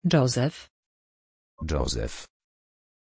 Pronunciation of Joseph
pronunciation-joseph-pl.mp3